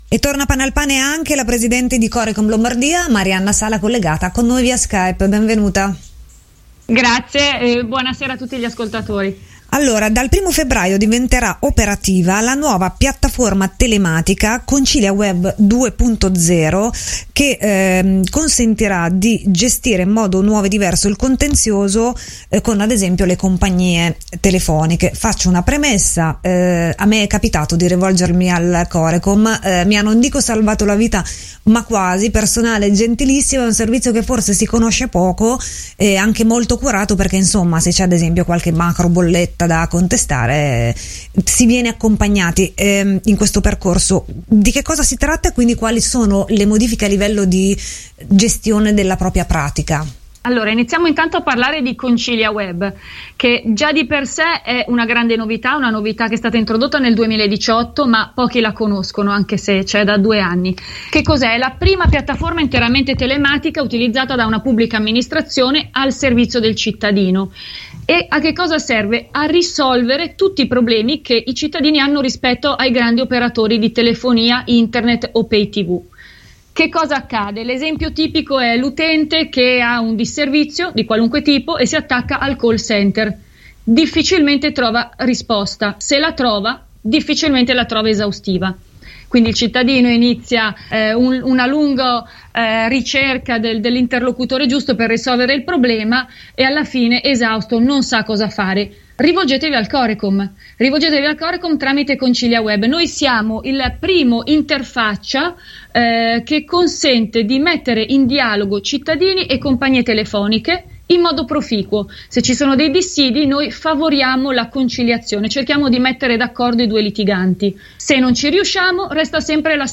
Intervista alla Presidente del Corecom Lombardia Marianna Sala sulle novità introdotte da Conciliaweb 2.0 (audio)